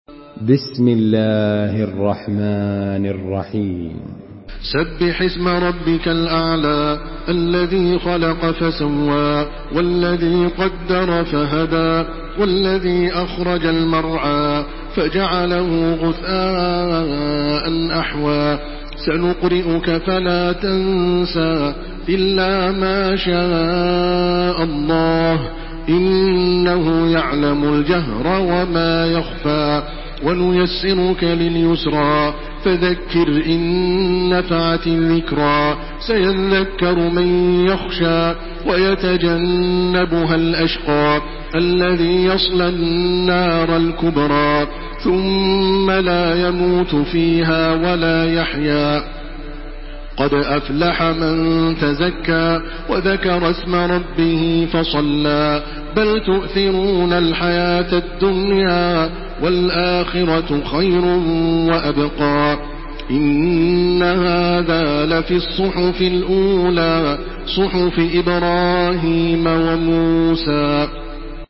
Surah Al-Ala MP3 by Makkah Taraweeh 1429 in Hafs An Asim narration.
Murattal